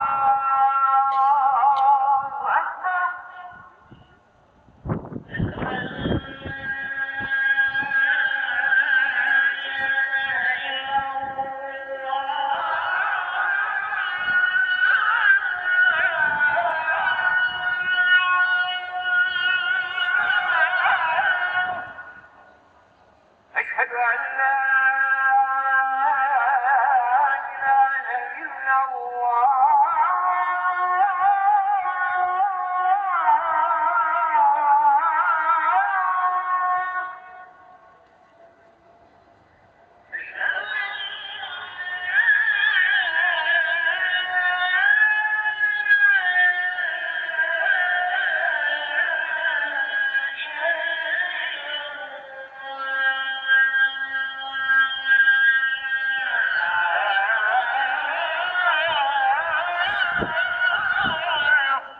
Pour voir cette carte postale il faut avoir un fond sonore exotique et pour cela il faut commencer par cliquer ici :
C’est l’appel des muezzins de deux mosquées (dont la mosquée bleue).
A l’heure H toutes les moquées font donner du haut parleur. C’est la cacophonie.